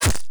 strike3.wav